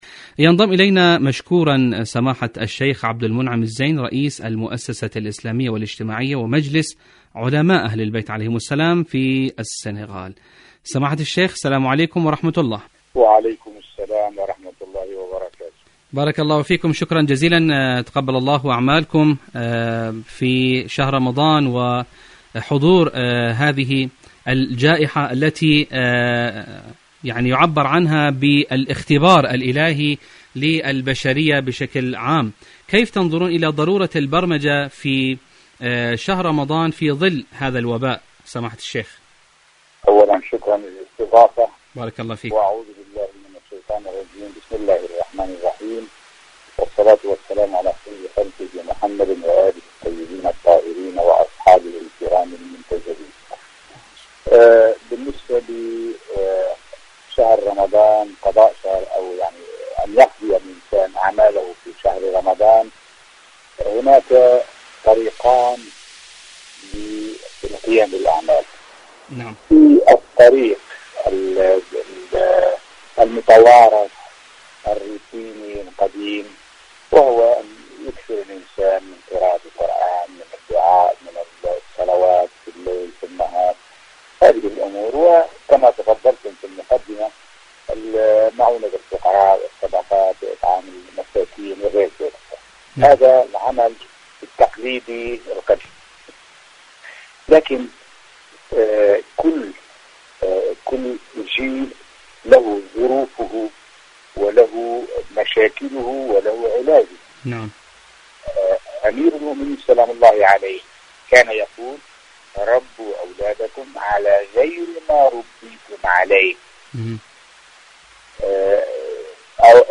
مقابلة
مقابلة إذاعية